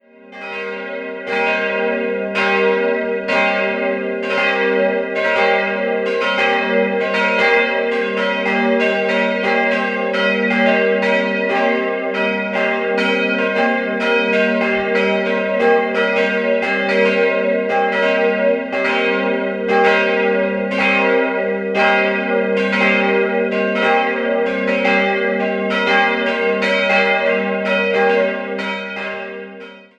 Im Jahr 1953 konnte die schlichte evangelische Christuskirche eingeweiht werden. Sehenswert ist die Altarwand des Münchner Malers Josef Reißl. 3-stimmiges Geläut: g'-b'-c'' Die Glocken wurden 1962 von der Gießerei Czudnochowsky in Erding gegossen.